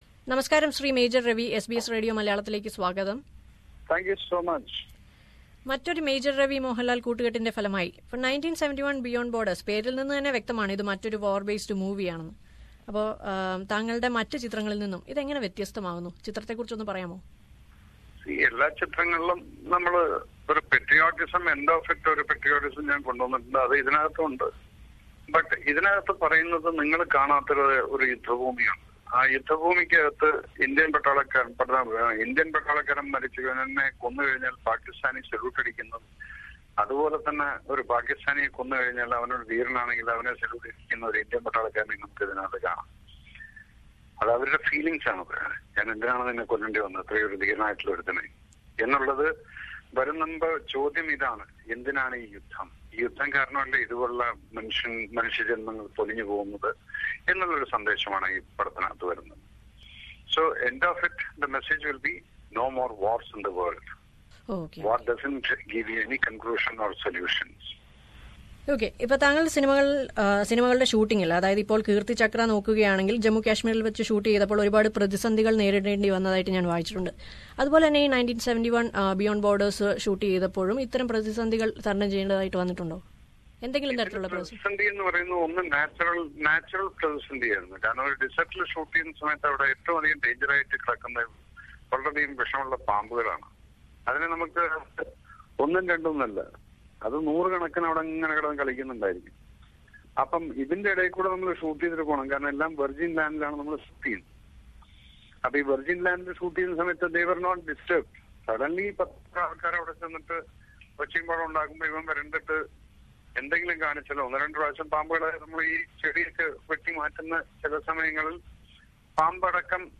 Interview with filmmaker Major Ravi